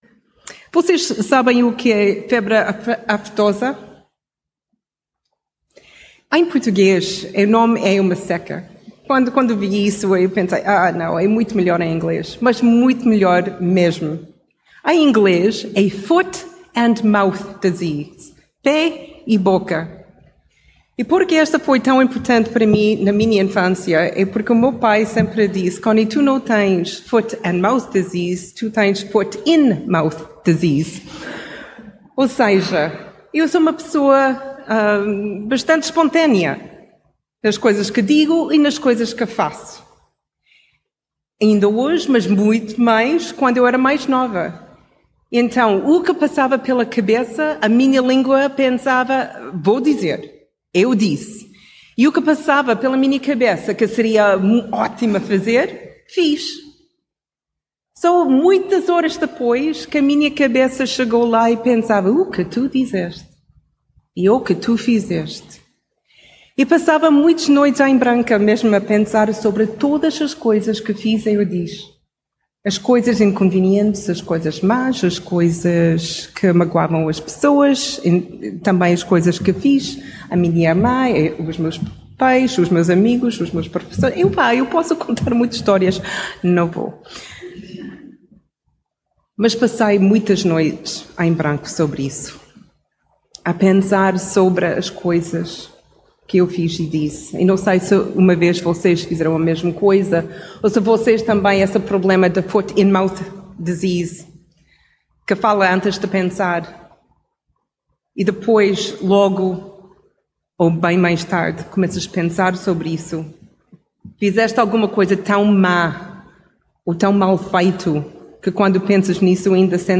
às escuras versus às claras mensagem bíblica Tudo começa com uma sensação de mal-estar.